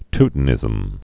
(ttn-ĭzəm, tyt-) also Teu·ton·i·cism (t-tŏnĭ-sĭzəm, ty-)